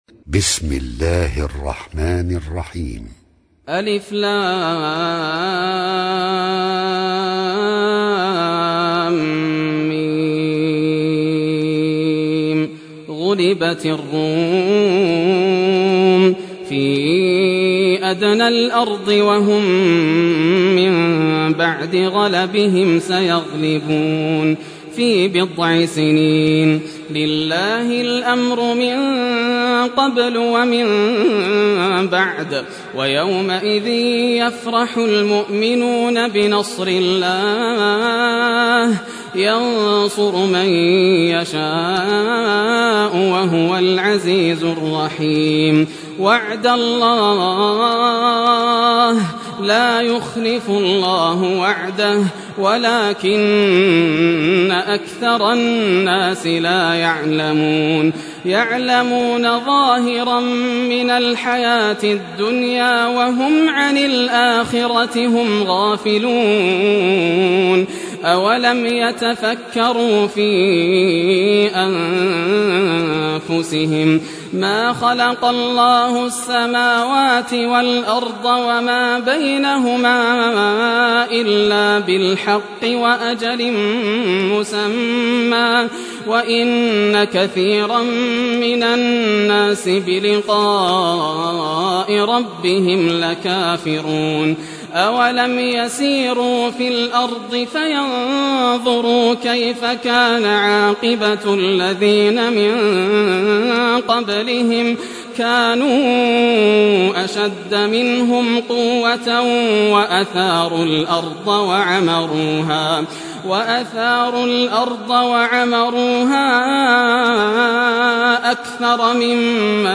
Surah Ar-Rum Recitation by Sheikh Yasser al Dosari
Surah Ar-Rum, listen or play online mp3 tilawat / recitation in Arabic in the beautiful voice of Sheikh Yasser Al Dosari.